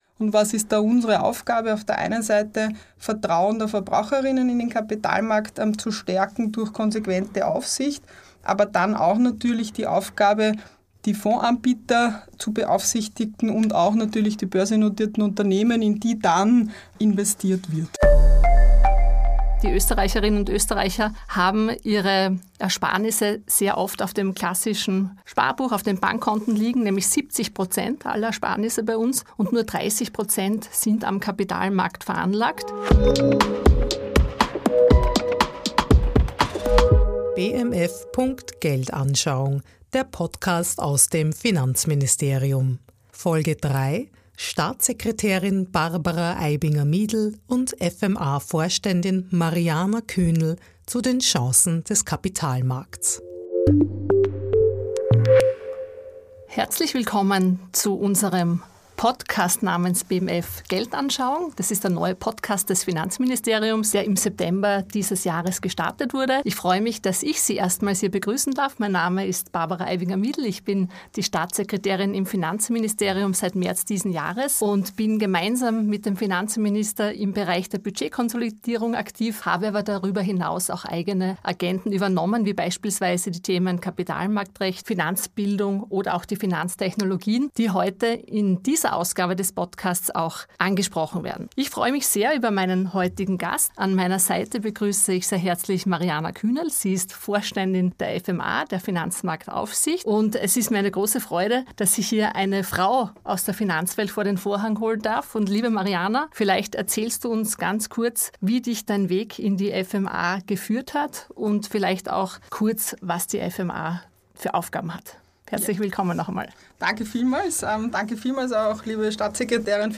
Vorständin der Finanzmarktaufsicht (FMA) Mariana Kühnel und Finanz-Staatssekretärin Barbara Eibinger-Miedl sprechen über den Kapitalmarkt, dessen Aufsicht und die Bedeutung der Finanzbildung.